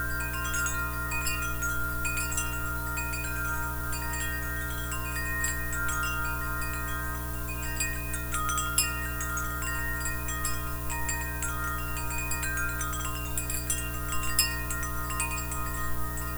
wind chimes.wav